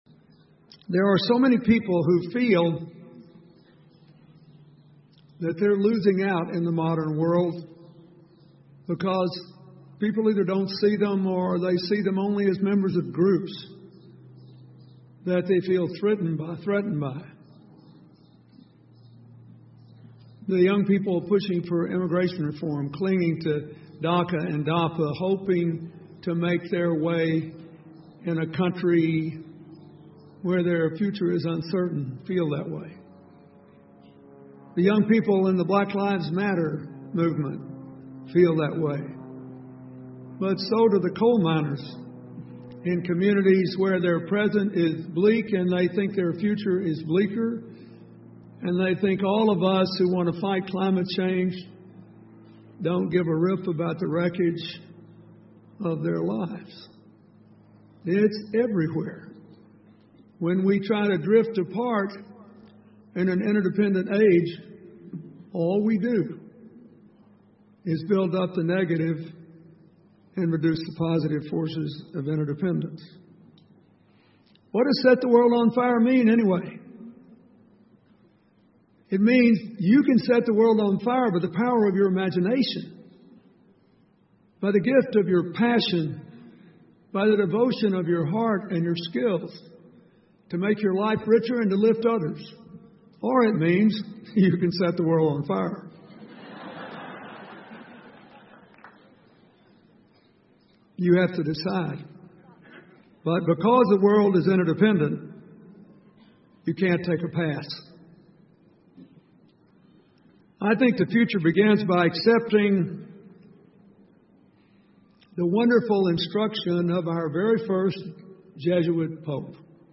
英文演讲录 比尔·克林顿：点燃世界(2) 听力文件下载—在线英语听力室